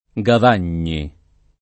[ g av # n’n’i ]